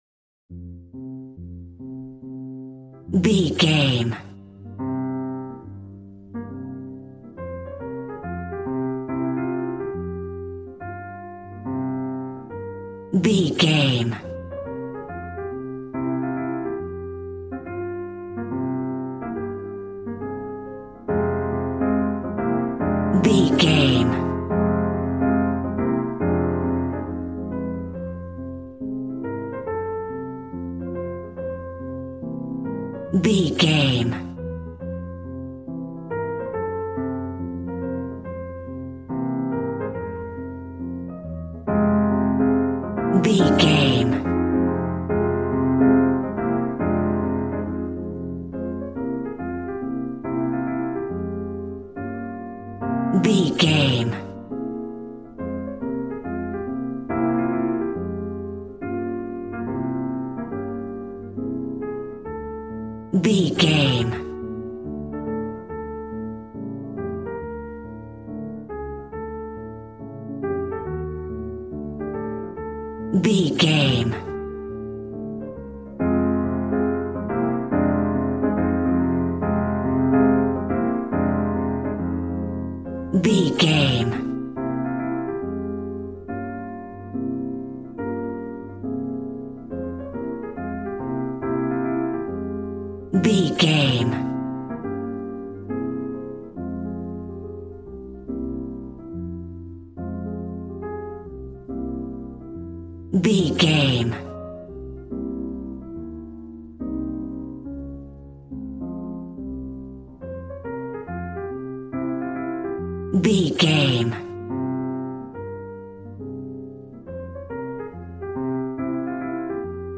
Aeolian/Minor